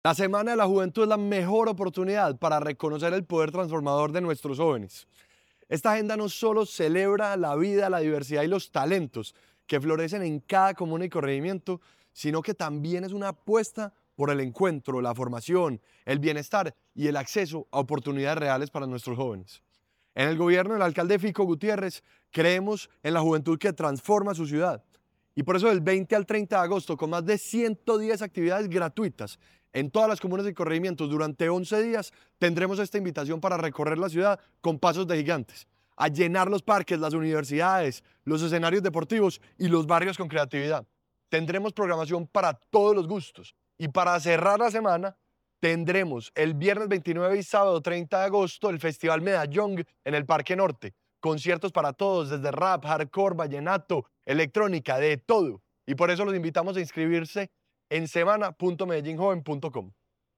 Declaraciones secretario de la Juventud, Ricardo Jaramillo Vélez
Declaraciones-secretario-de-la-Juventud-Ricardo-Jaramillo-Velez.mp3